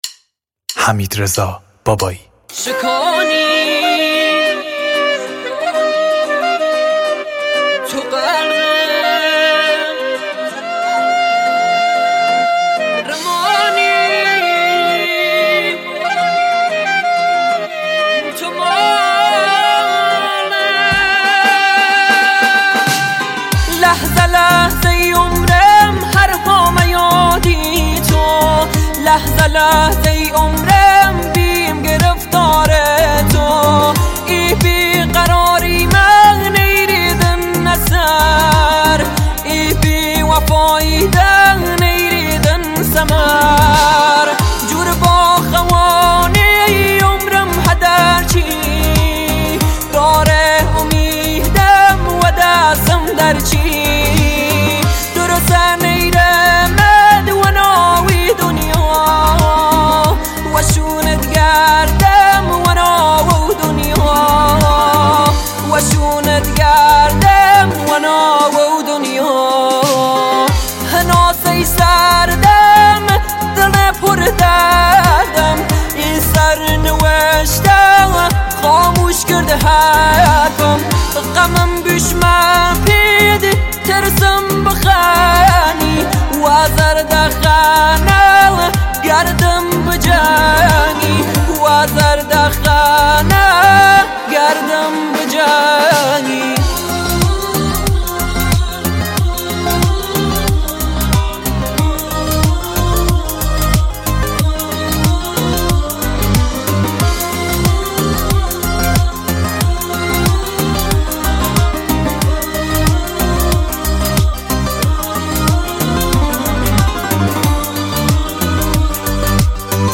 بسیار زیبا و غمگین